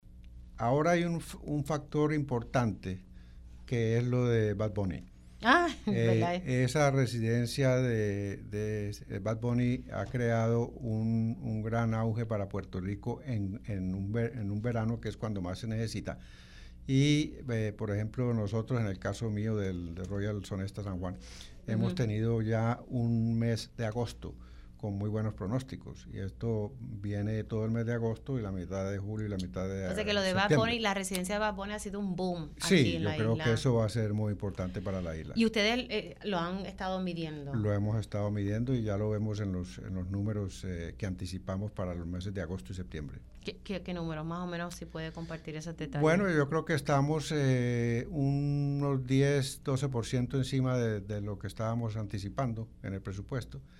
Si no lo produzco, pues me lo quitan“, indicó el hotelero en entrevista para Pega’os en la Mañana.